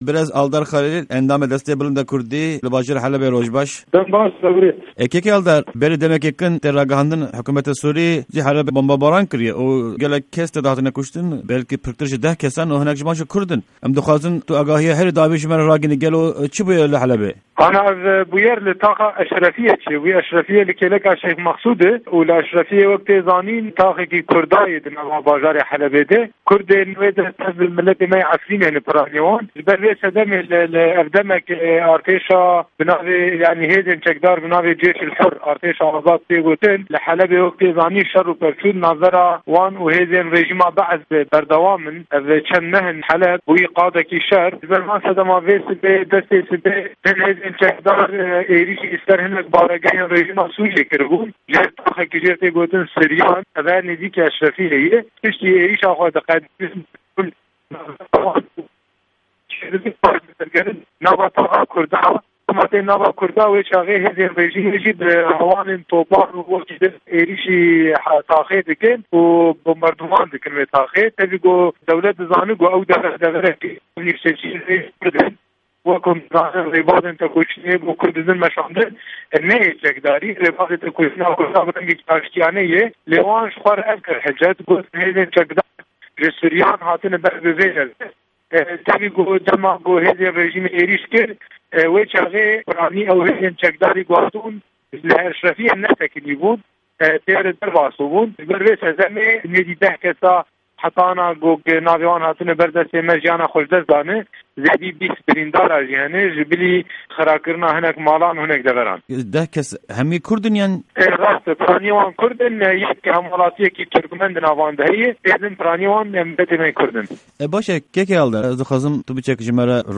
Endamê Desteya Bilind a Kurdî Aldar Xelîl, di hevpeyvîneke taybet de ji Pişka Kurdî ya Dengê Amerîka de agahîyên herî dawî dide guhdarên me.